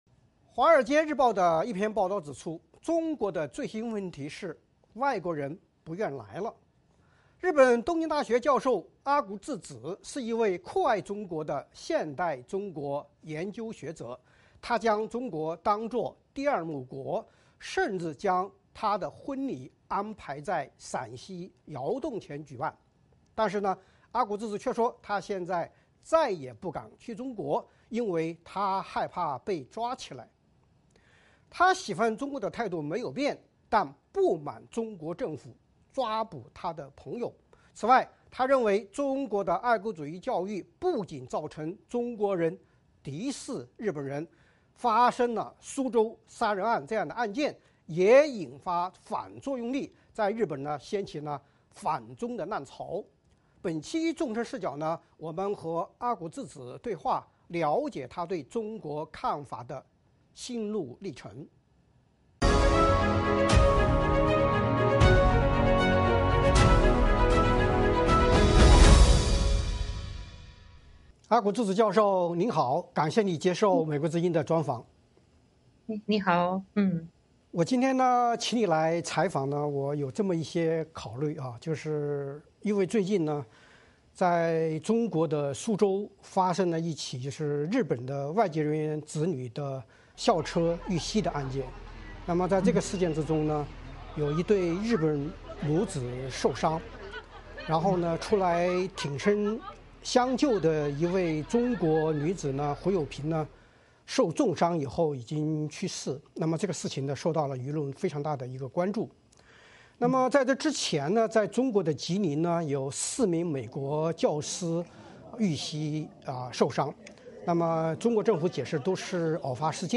《纵深视角》节目进行一系列人物专访，受访者发表的评论不代表美国之音的立场。